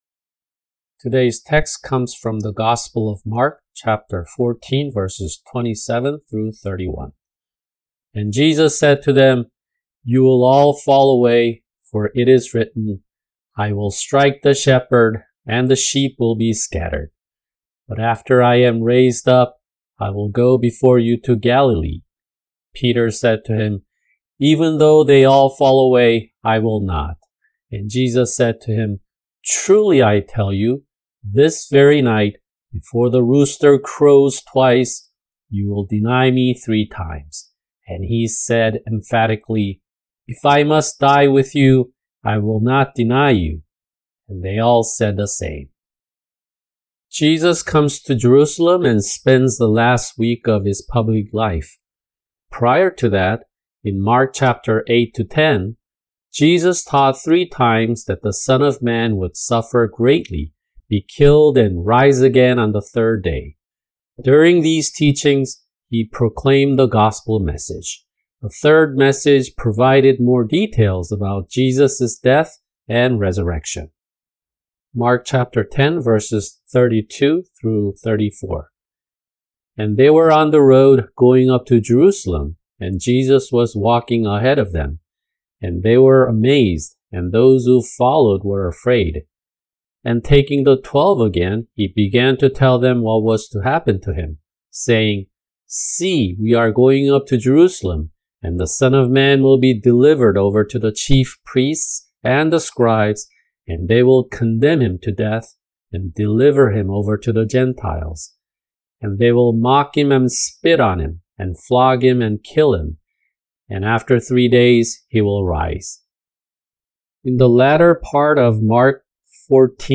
[주일 설교] 마가복음(67) 14:32-42(1)